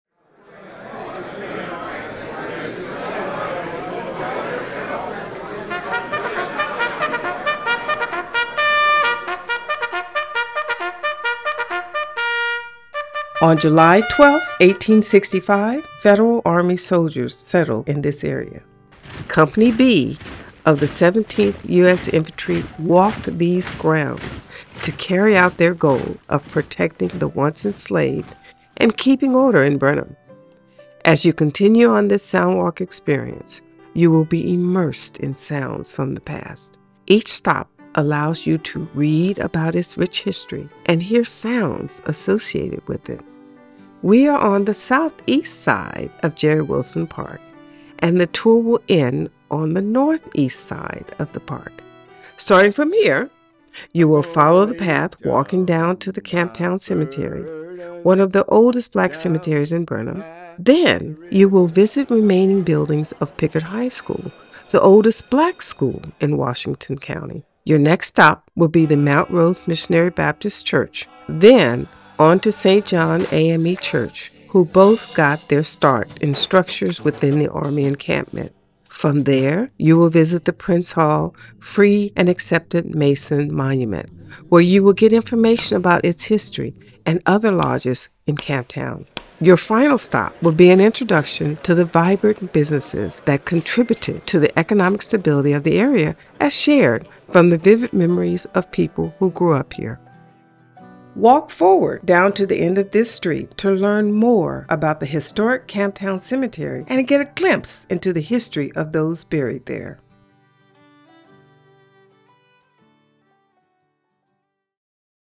You will go on a tour that includes sounds and stories of the area.